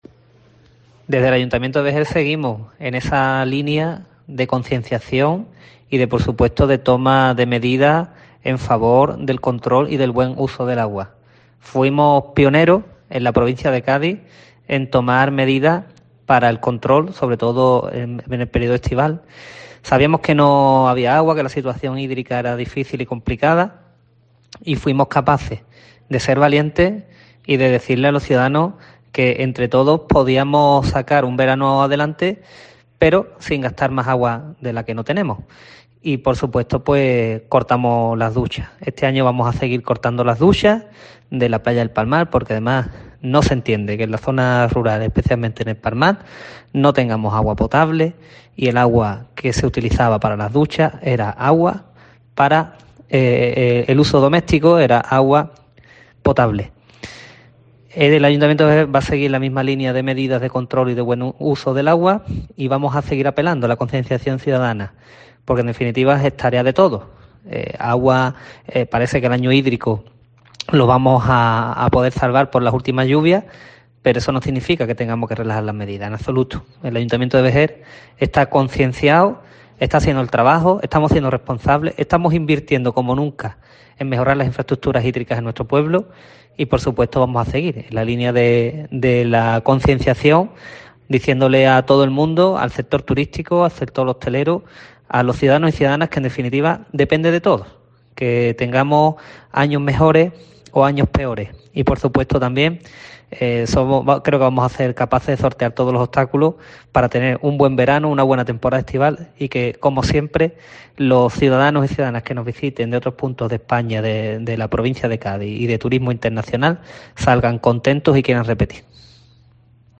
El alcalde de Vejer sobre las medidas para luchar contra la sequía